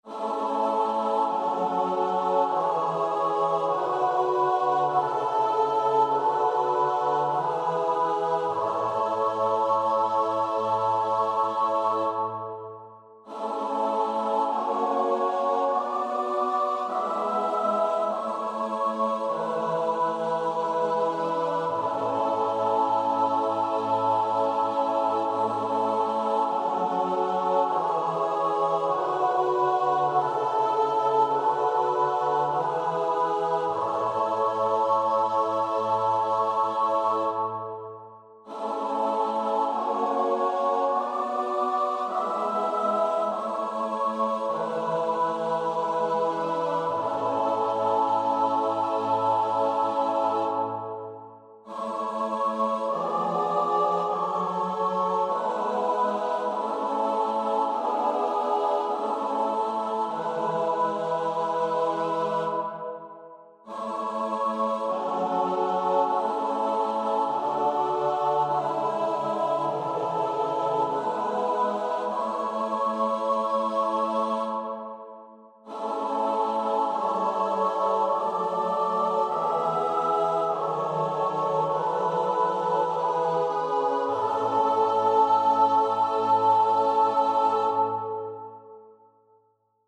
4-Part Chorales that aren’t Bach
Comments: The iii chord is used here in a major key. Voice overlap is more present in the last two phrases.